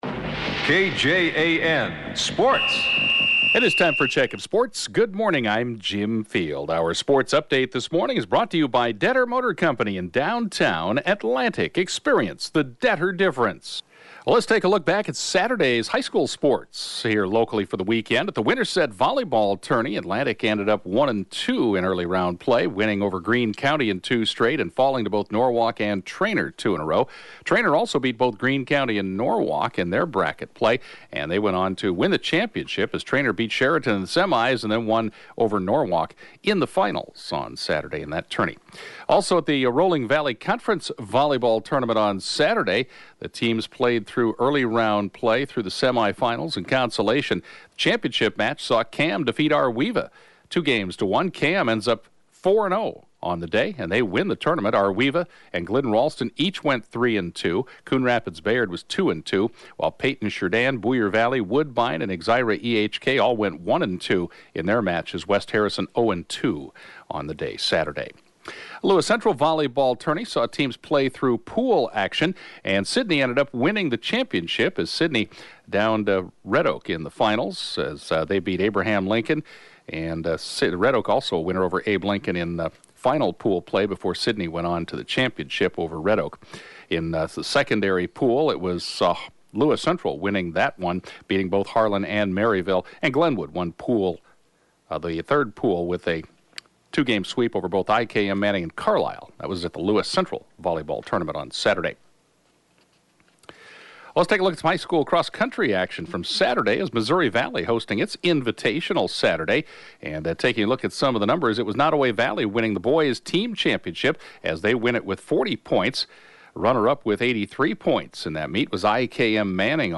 (Podcast) KJAN Morning Sports report, 10/15/2018